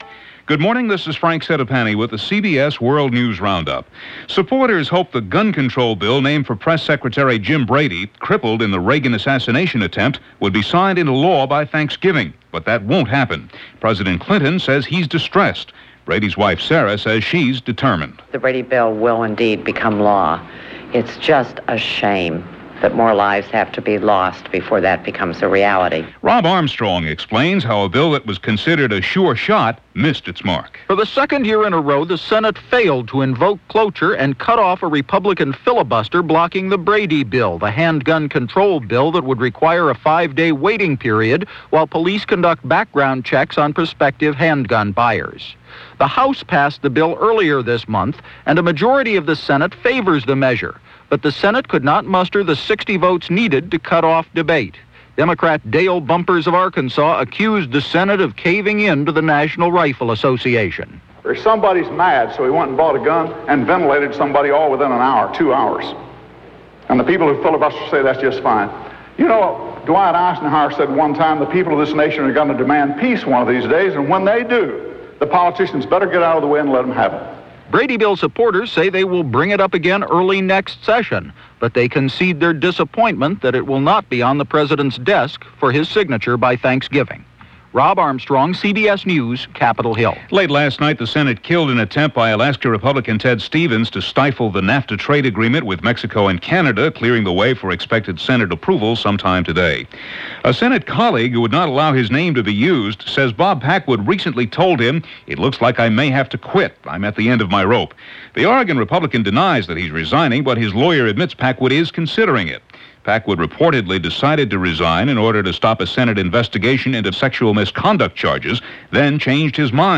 And that’s a very small slice of what went on, this November 20, 1993 as reported by The CBS World News Roundup.